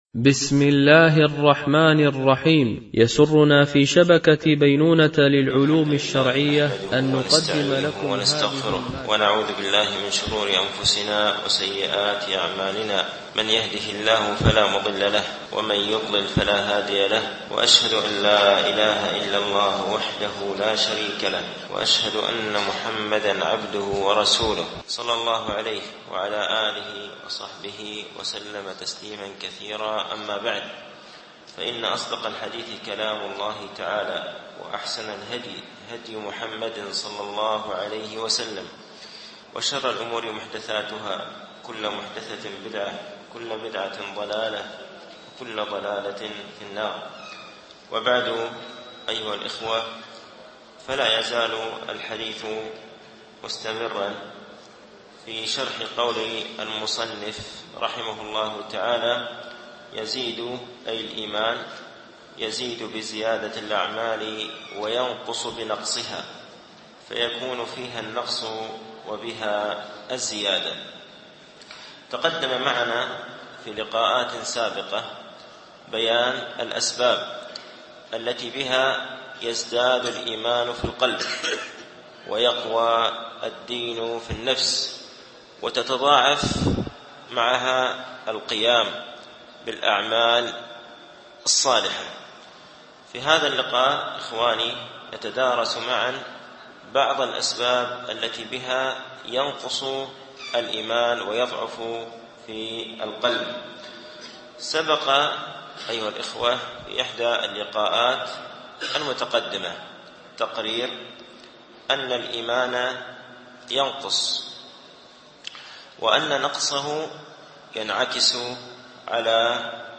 شرح مقدمة ابن أبي زيد القيرواني ـ الدرس الثاني و الستون
MP3 Mono 22kHz 32Kbps (CBR)